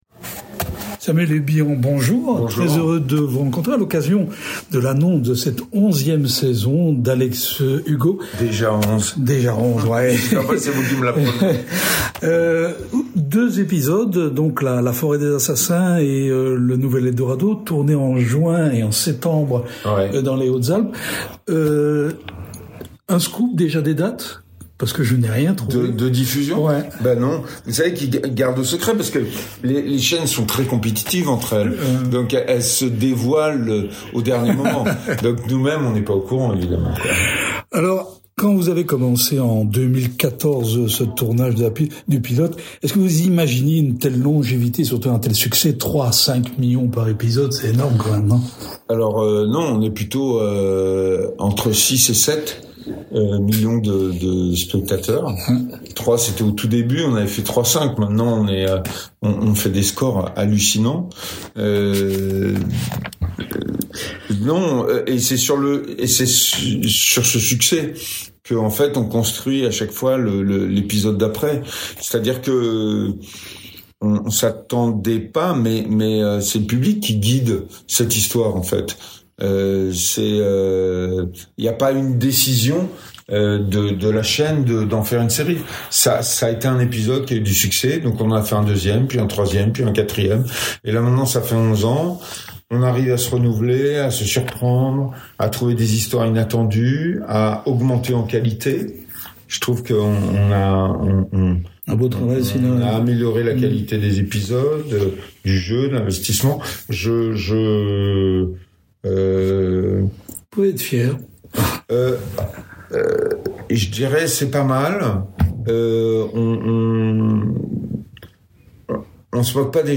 Rencontre. On y parle de cascade, de migrants, d’écologie, de drogue, de tendresse et d’amour, de radicalisation, de l’Actors Studio…